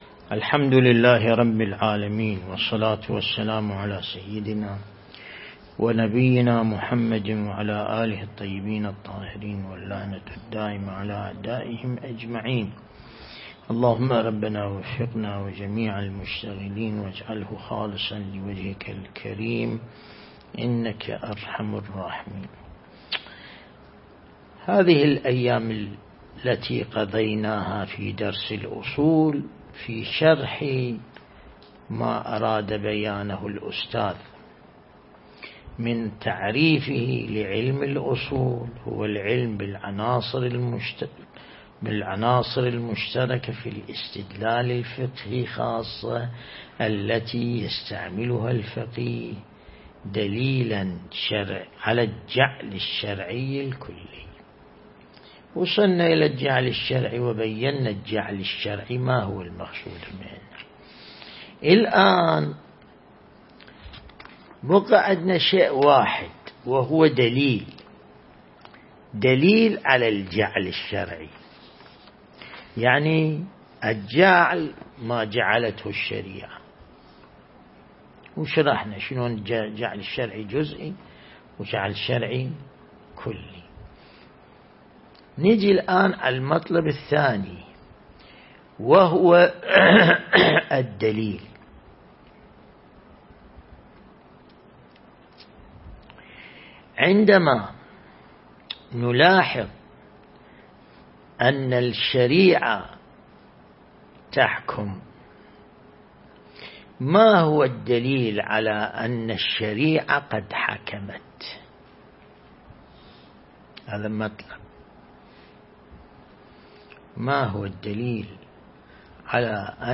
درس
النجف الأشرف